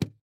click-close.wav